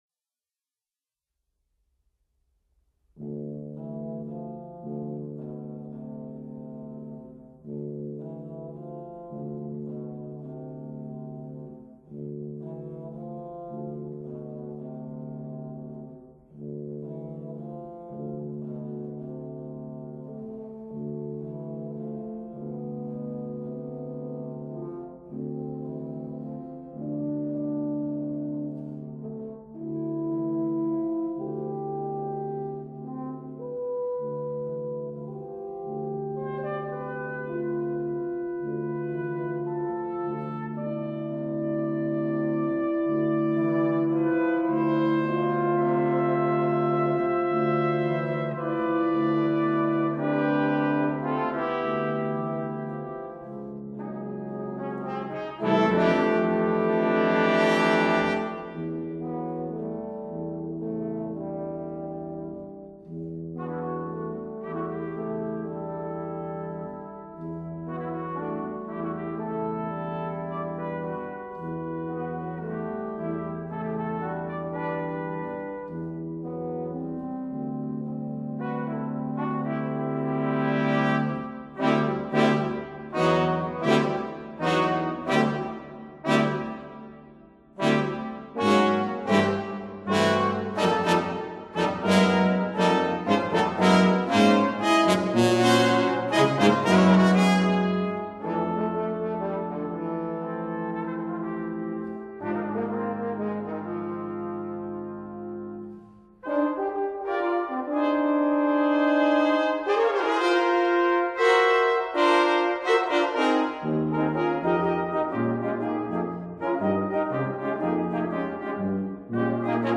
Portuguese music for brass